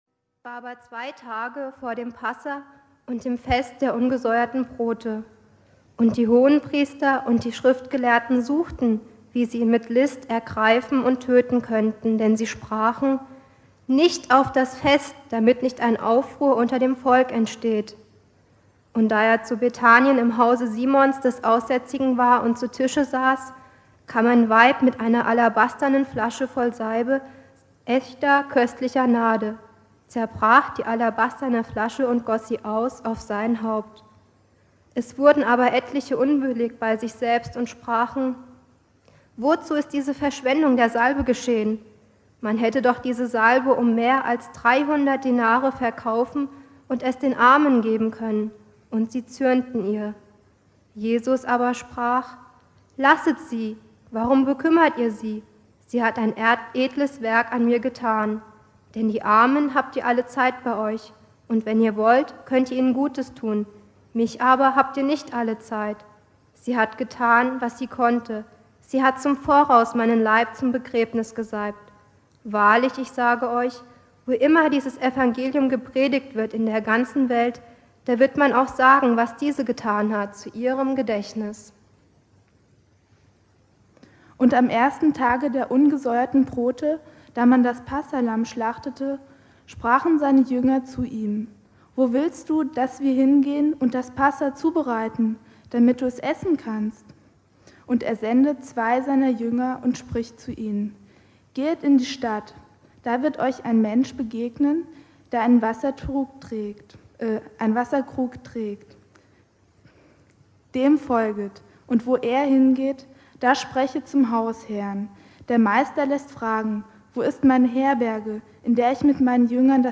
Predigt
Gründonnerstag
Schriftlesung von Jugendlichen des Jugendkreises III